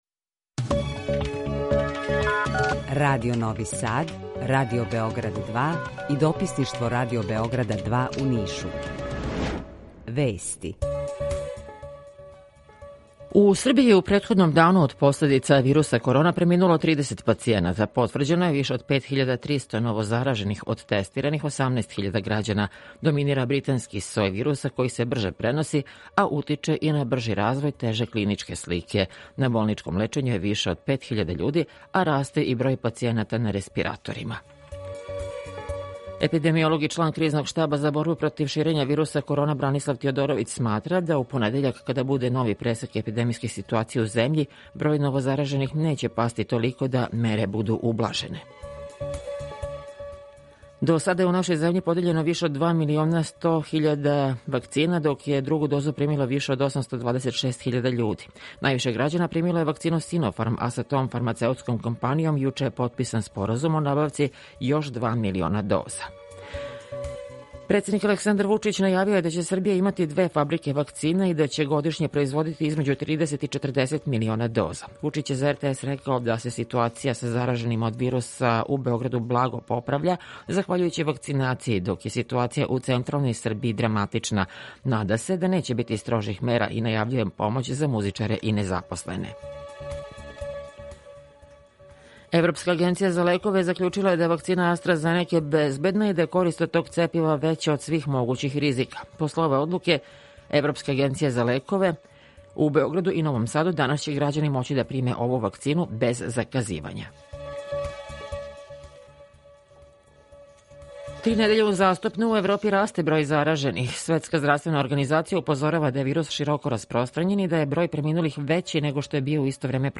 Укључење Бањалукe
Јутарњи програм из три студија
У два сата, ту је и добра музика, другачија у односу на остале радио-станице.